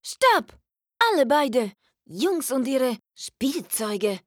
The original dubbing voices of Bond, M, and Tanner joined ”Her Majesty’s secret service” for this project.